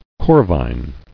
[cor·vine]